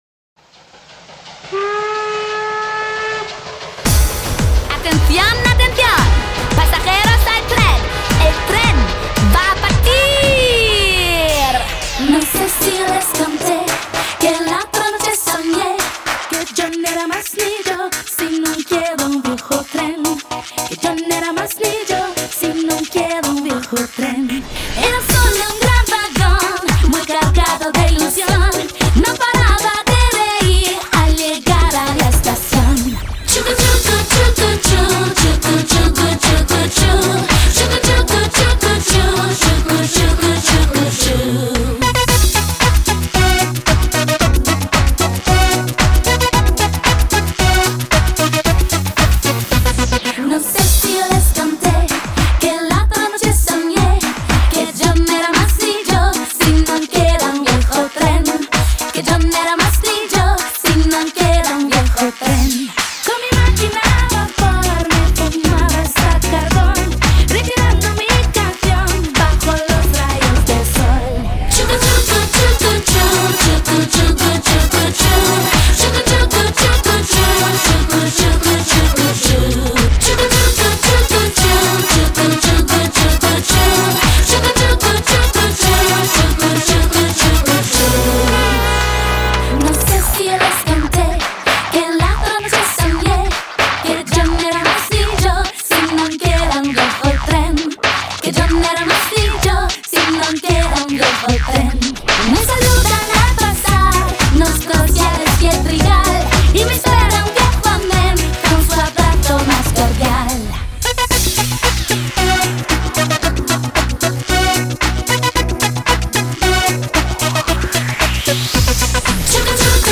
01-Паровоз.mp3